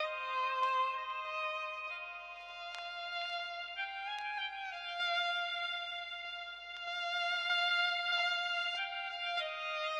MELODICS 1.wav